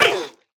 Minecraft Version Minecraft Version 25w18a Latest Release | Latest Snapshot 25w18a / assets / minecraft / sounds / mob / armadillo / hurt2.ogg Compare With Compare With Latest Release | Latest Snapshot
hurt2.ogg